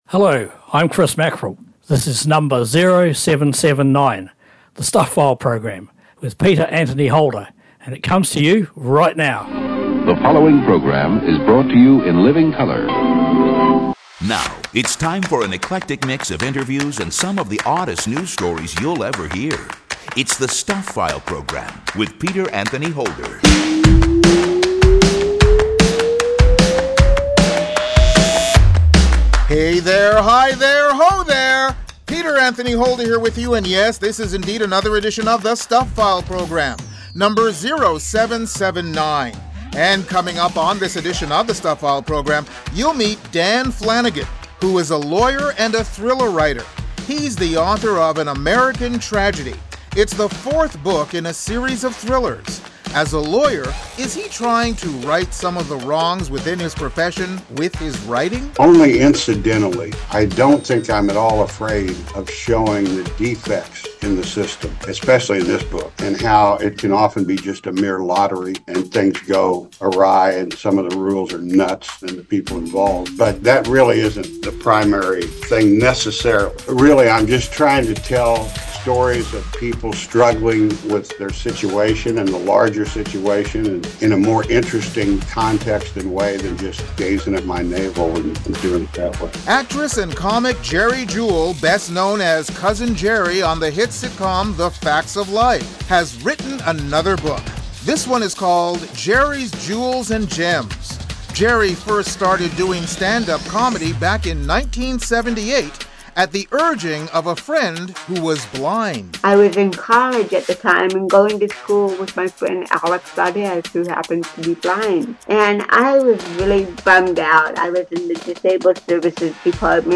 The Stuph File Program Interview